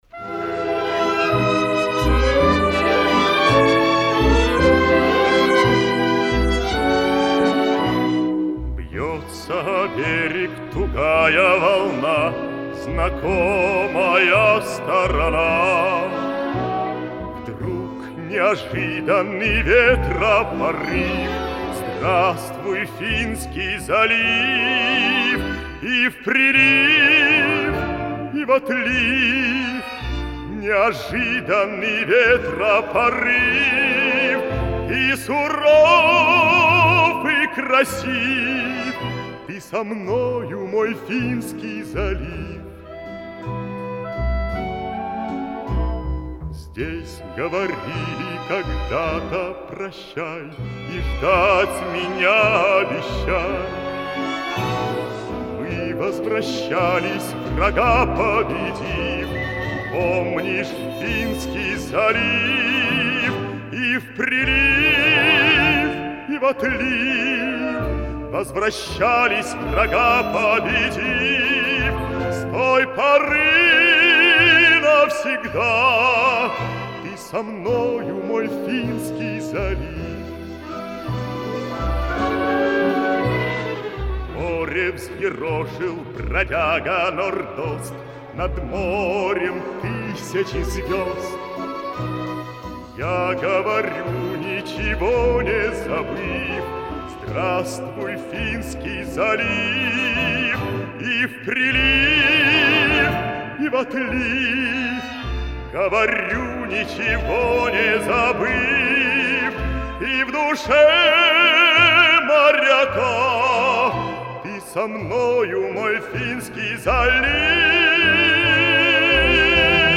Поздняя "ленинградская" песня